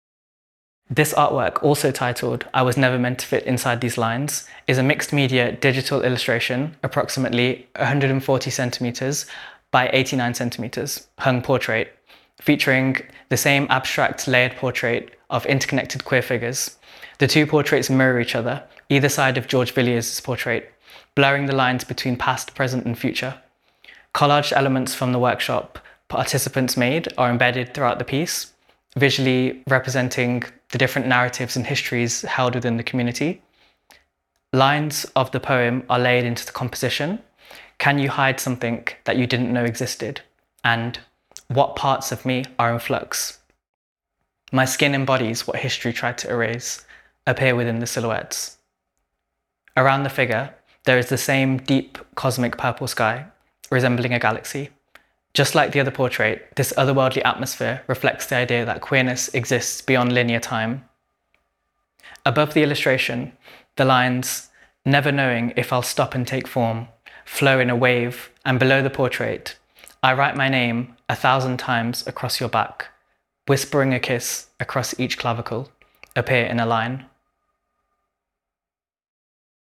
We Have Always Been Here – Audio Descriptions of Exhibition Artwork
Artwork descriptions – audio